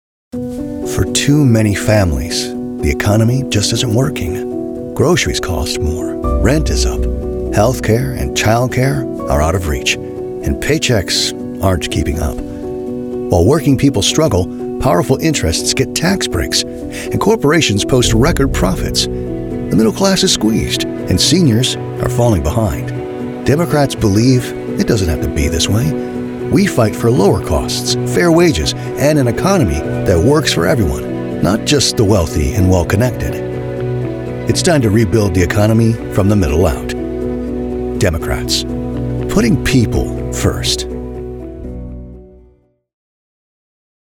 Male Democratic Voices
Voice actors with deep experience, pro home studios and Source Connect.